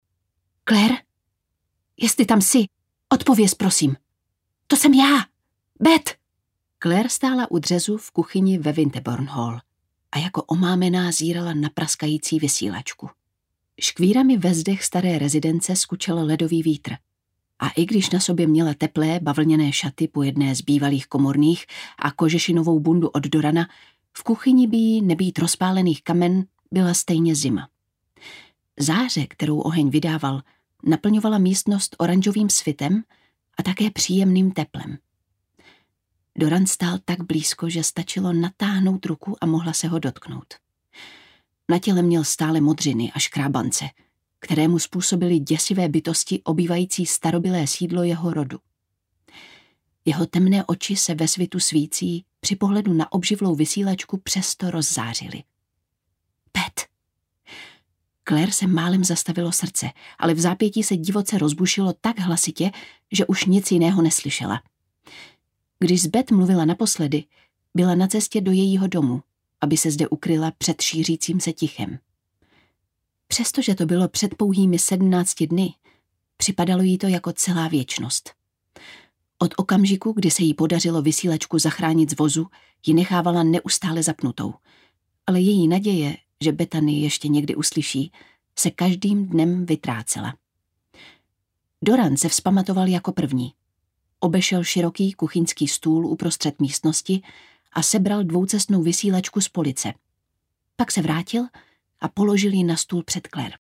Výkřiky v temnotě audiokniha
Ukázka z knihy
• InterpretLucie Vondráčková